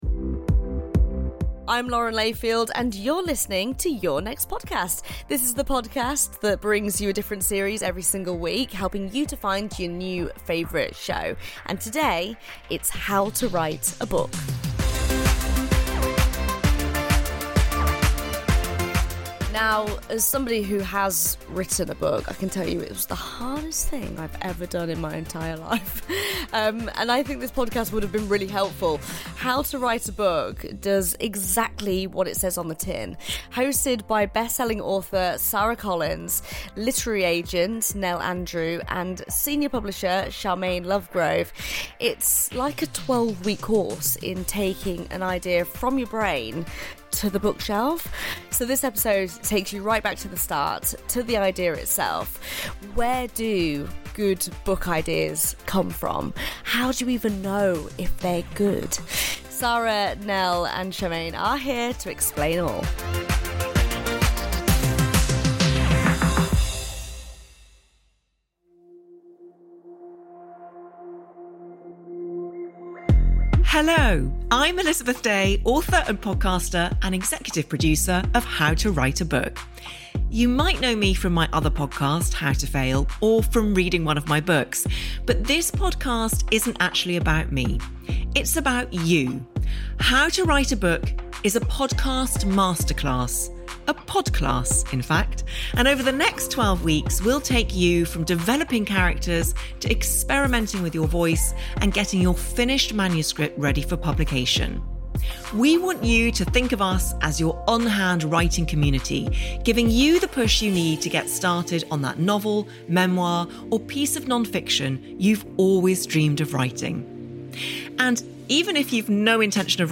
Lauren Layfield introduces How to Write a Book on the series recommendation show Your Next Podcast.